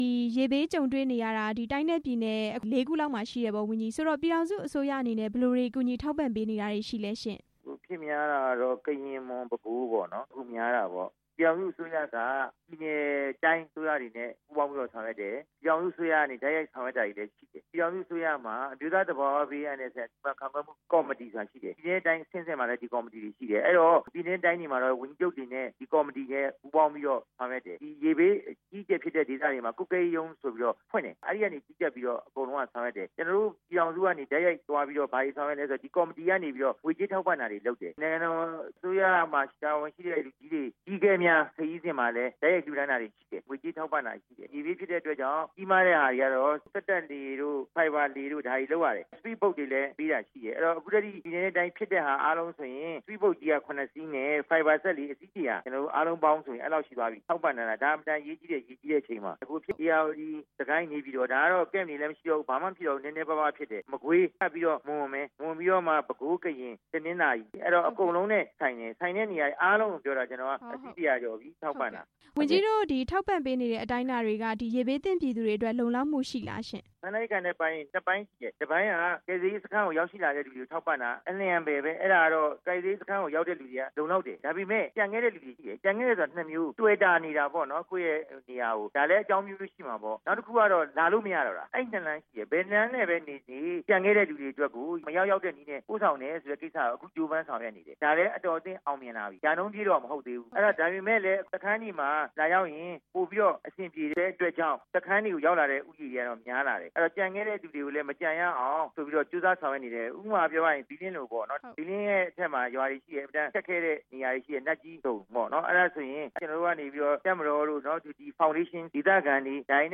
ရေဘေးကယ်ဆယ်ရေး ဝန်ကြီးနဲ့ မေးမြန်းချက်